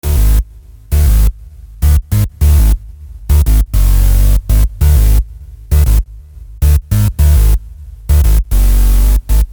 Bass 18.wav